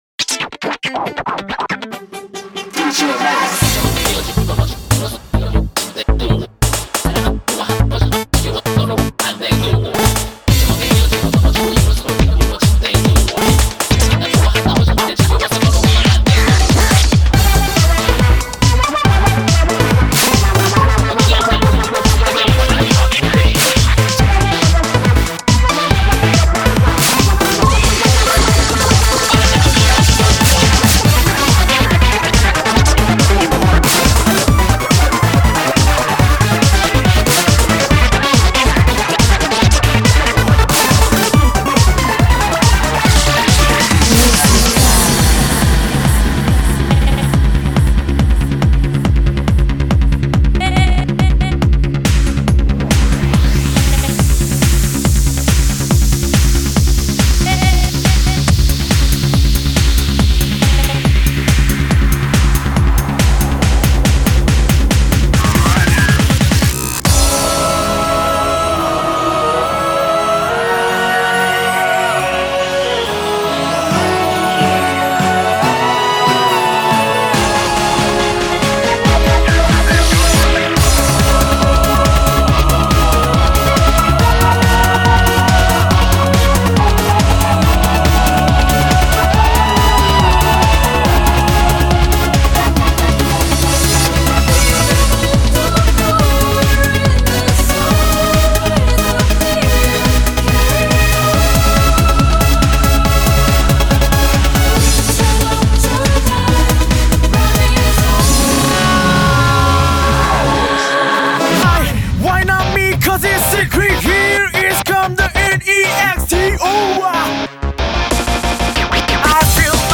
BPM140-185